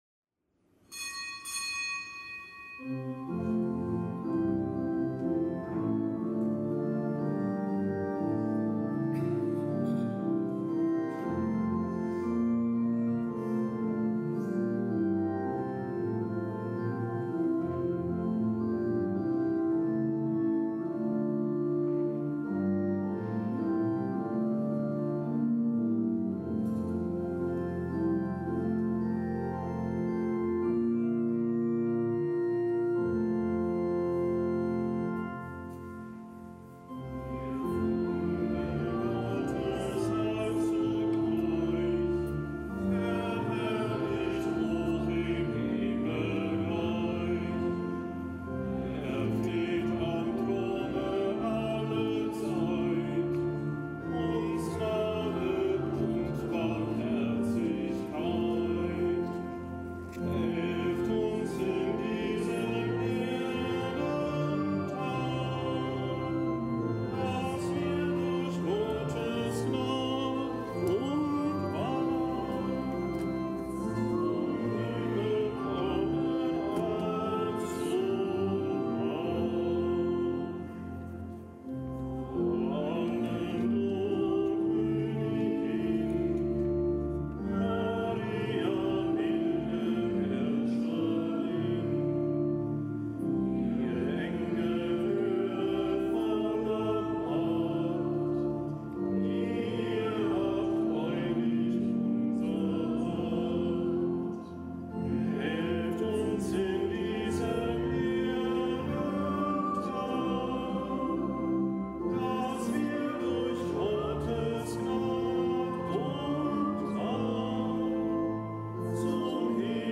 Kapitelsmesse aus dem Kölner Dom am Freitag der 29. Woche im Jahreskreis.